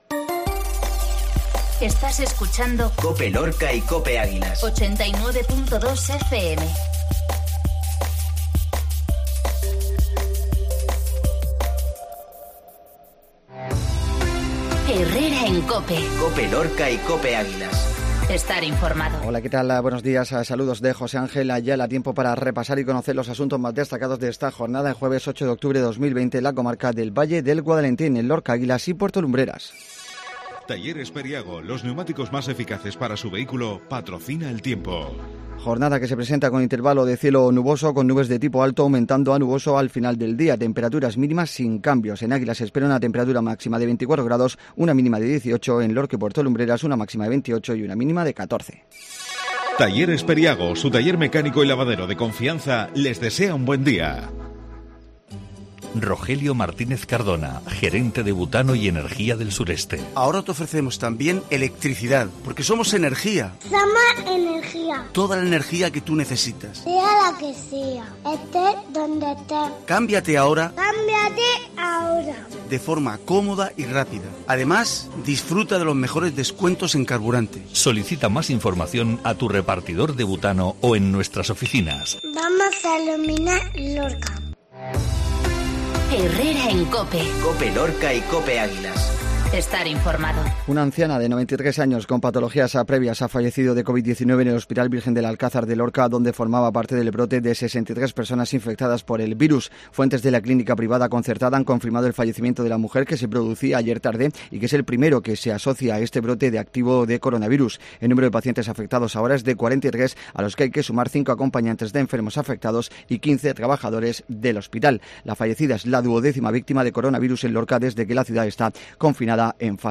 INFORMATIVO COPE LORCA JUEVES 0810